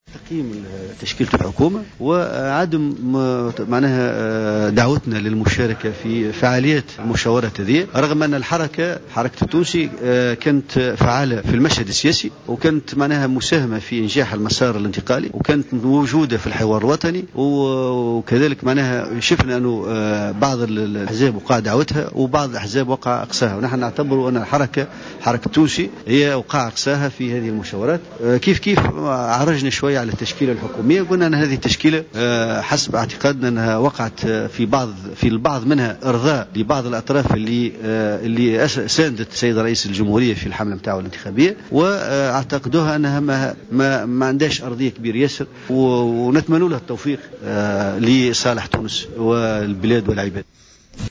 Dans une déclaration accordée à Jawhara FM